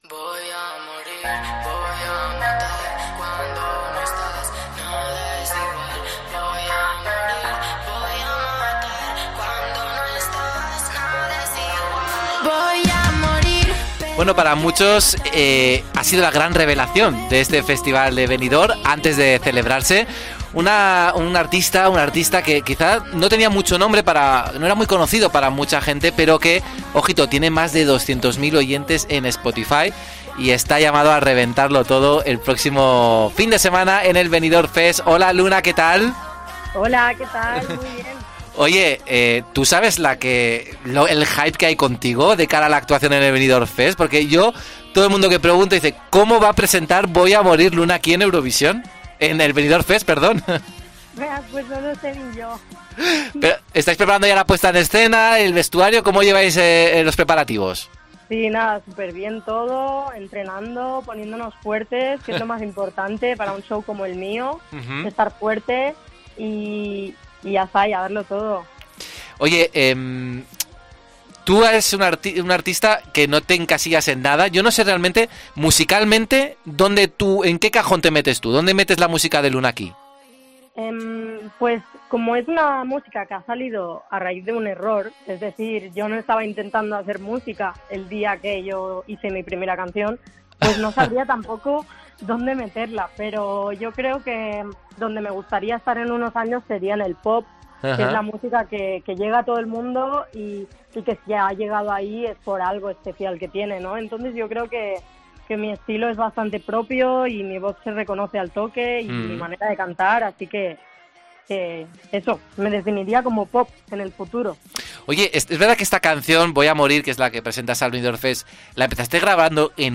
Luego ya la llevé a un estudio y fue cogiendo forma", explica en una entrevista al programa "Pasaporte a Eurovisión" de COPE.